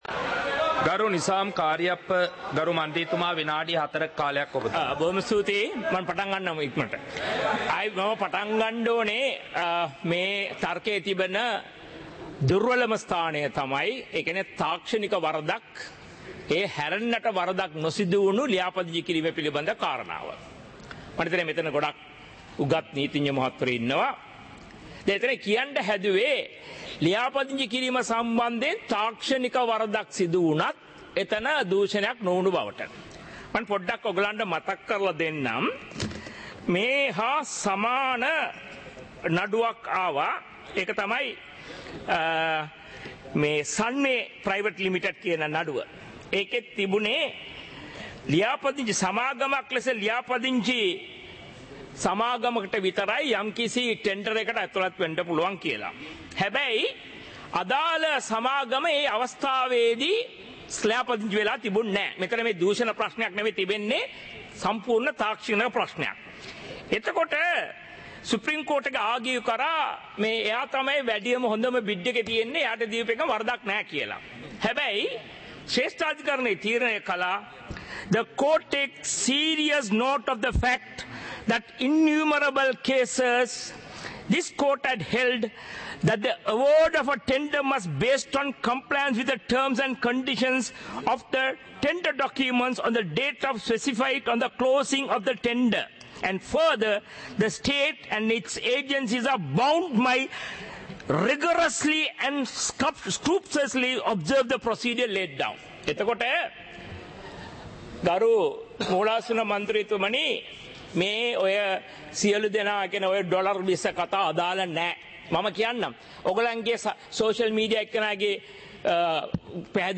Proceedings of the House (2026-04-10)
Parliament Live - Recorded